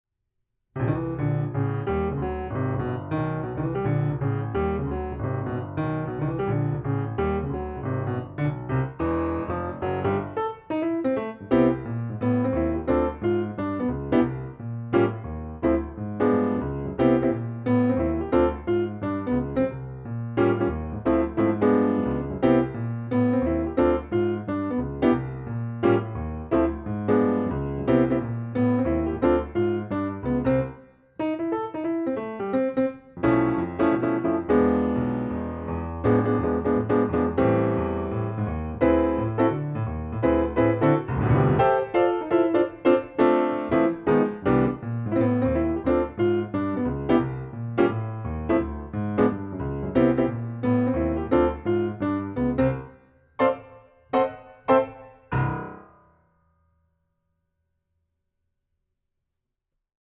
Tunes are recorded in our studio.